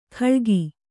♪ khaḷgi